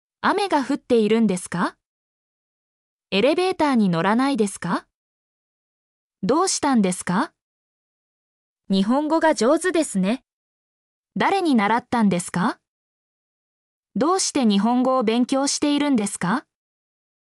mp3-output-ttsfreedotcom-10_xBlP26ej.mp3